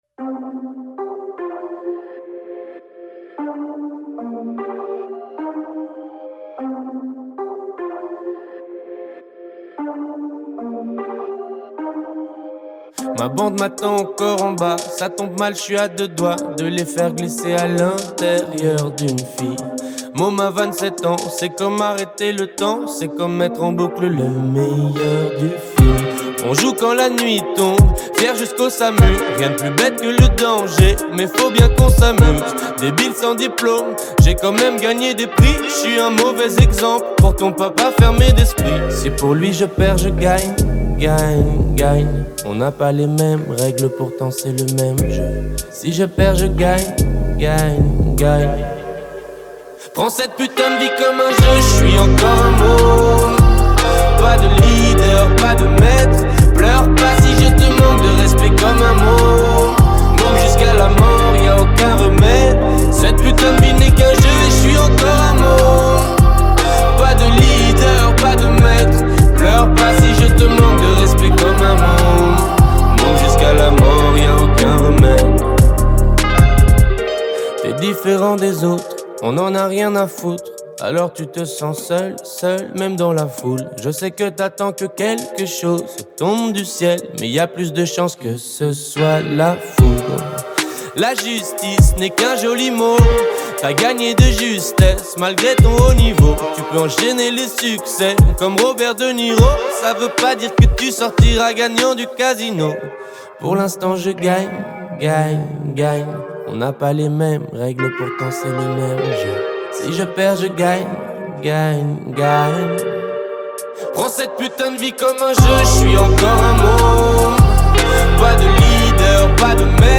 0/100 Genres : raï, moroccan chaabi Écouter sur Spotify